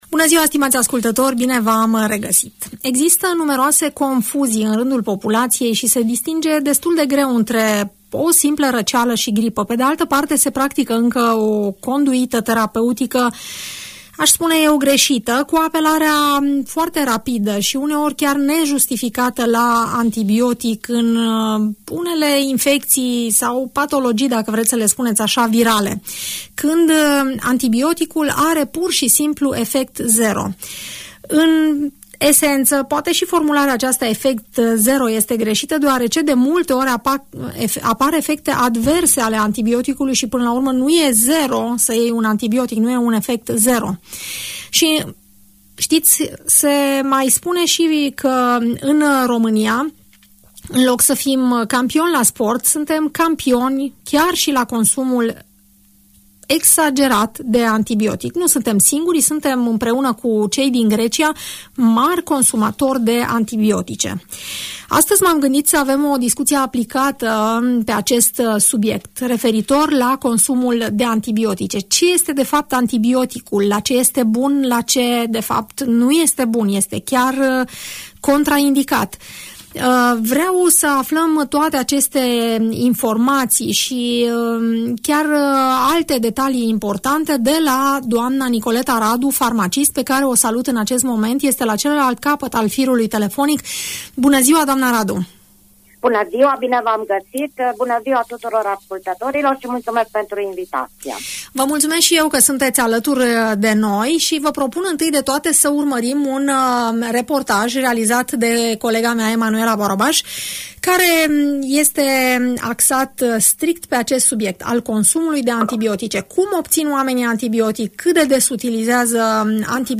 le primim în emisiunea „Părerea ta”, realizată la Radio Tg Mureș